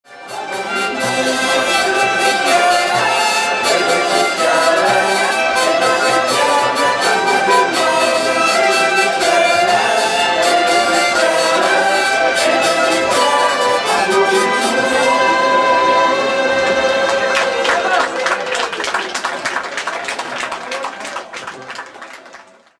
LA FÊTE DE LA VIELLE
repris en choeur par la foule...